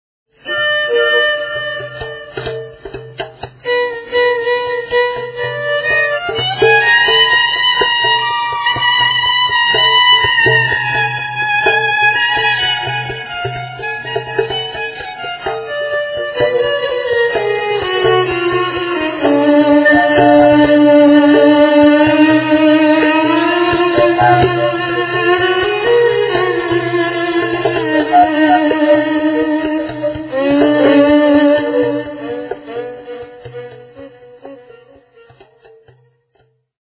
For those, who are not "in the picture" - Fujara is Slovak traditional wood instrument (a thing looking like a "big pipe"), with characteristic, unreplaceable sound. Here are my fujara´s improvisations with different musicians, alone, live, studio, etc...
fujara, percussion
violin